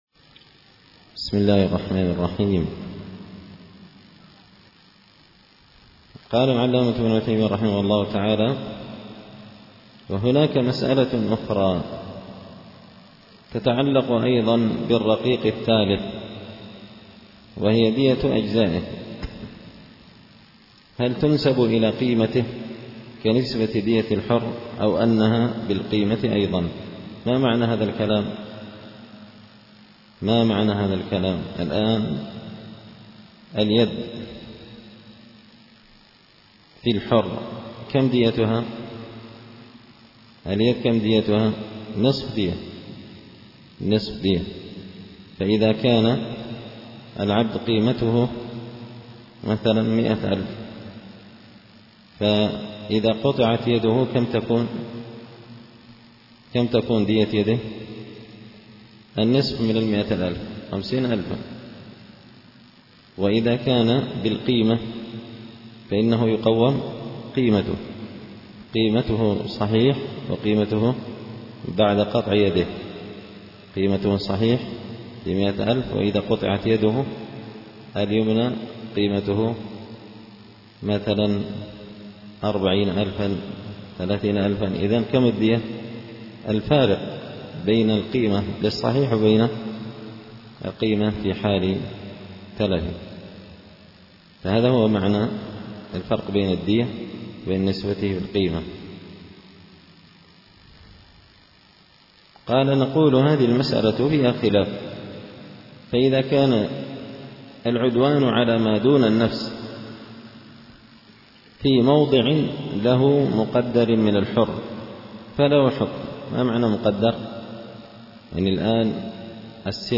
التعليقات على نظم الورقات ـ الدرس 55
دار الحديث بمسجد الفرقان ـ قشن ـ المهرة ـ اليمن